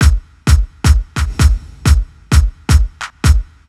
Downtown House/Loops/Drum Loops 130bpm